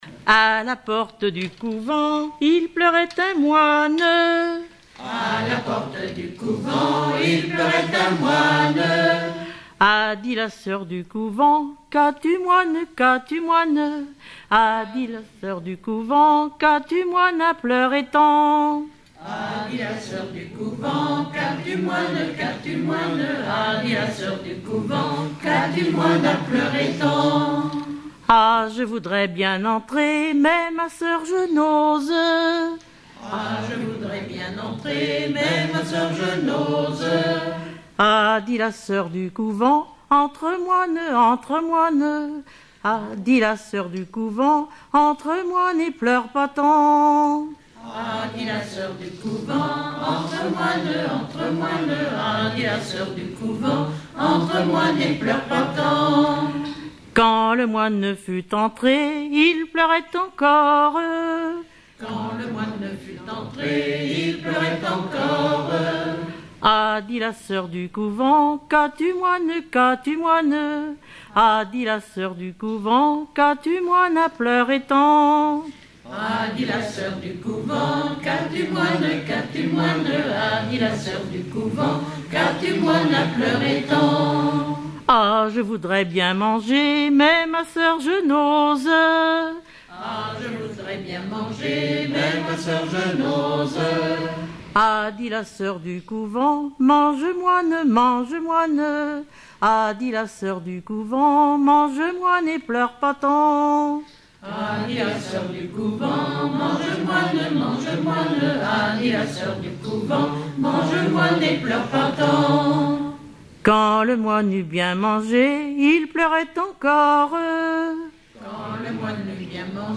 Mémoires et Patrimoines vivants - RaddO est une base de données d'archives iconographiques et sonores.
Genre énumérative
Chansons traditionnelles et populaires
Pièce musicale inédite